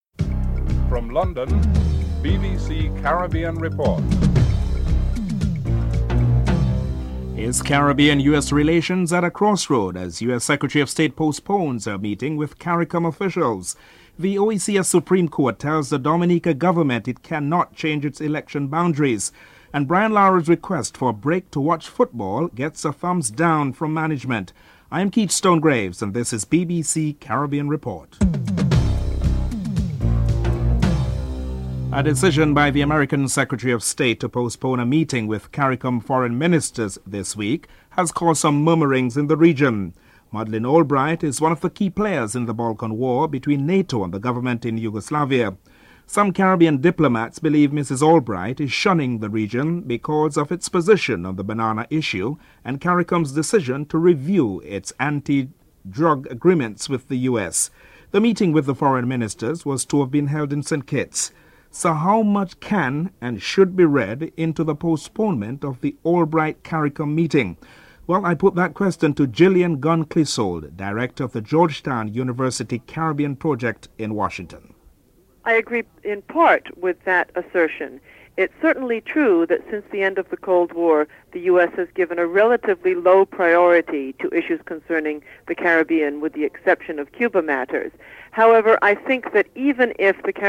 7. In the second of a series of reports BBC correspondent analyses the business side of the World Cup series hosting interviews with players and administrators (13 :12 – 14: 32)